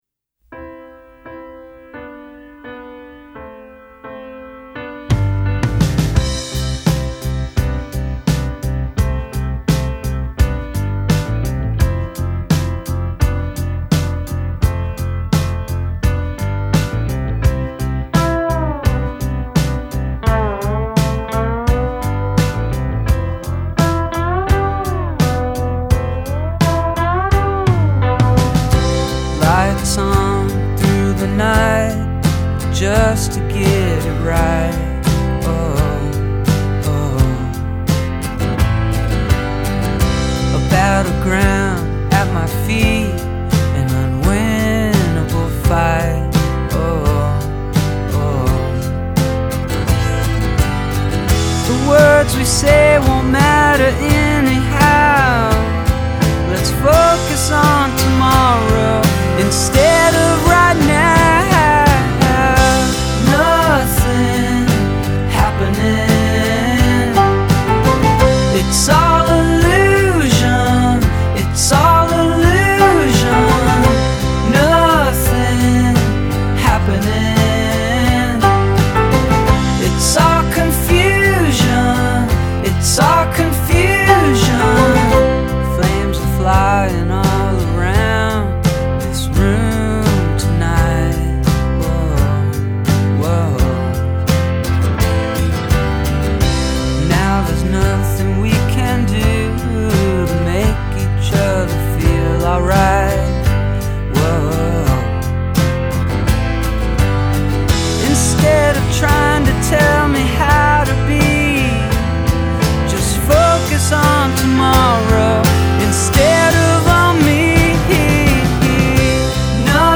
This Indie pop makes any nice summer day that much nicer.